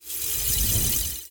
failsound.ogg